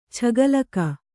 ♪ chagalaka